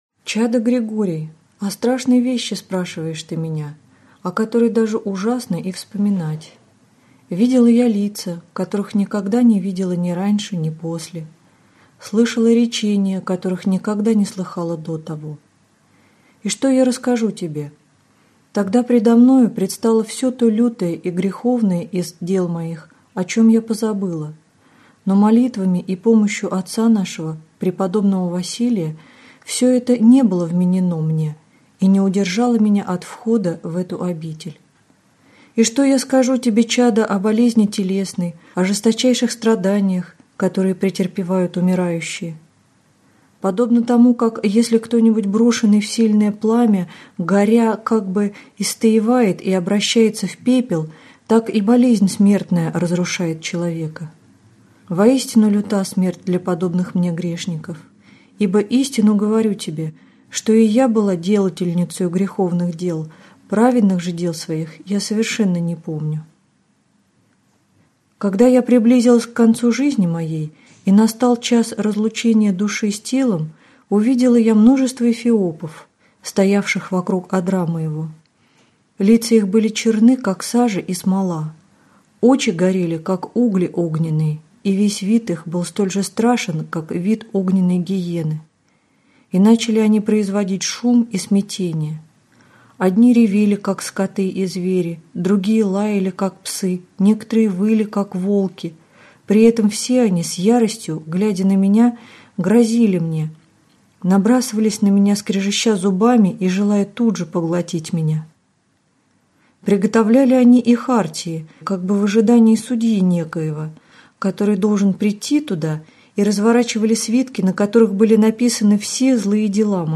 Аудиокнига Духовник. Как пройти воздушные мытарства | Библиотека аудиокниг